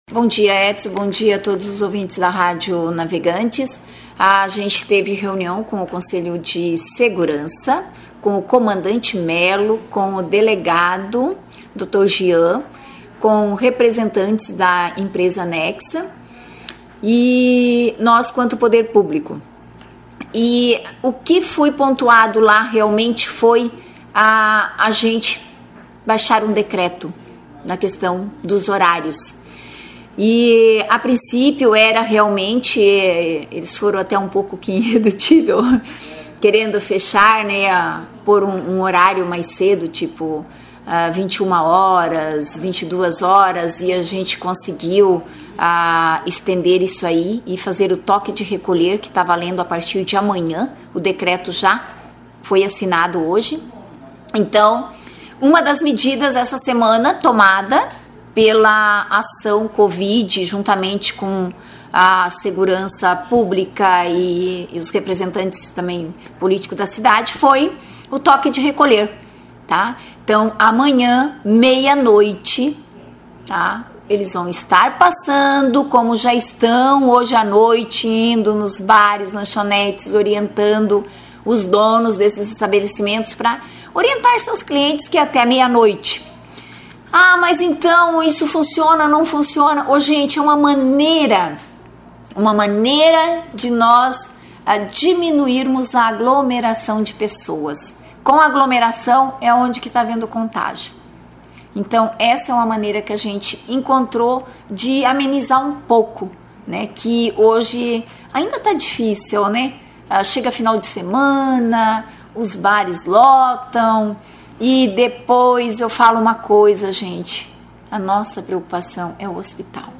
Em entrevista exclusiva, concedida nesta quinta-feira (4) para o Informativo Navegantes FM, a prefeita Seluir Peixer  anunciou um novo decreto que passa a valer a partir desta sexta-feira (5), com Toque de Recolher, da meia noite até as cinco horas da manhã (00h00 às 05h00).